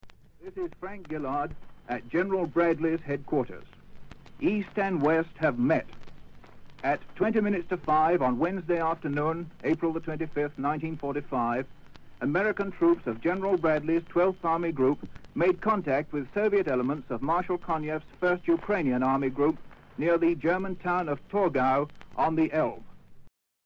Описание: Link-Up With Russian Army - War Report 25.04.45
Исполняет: Frank Gillard Исполнение 1945г.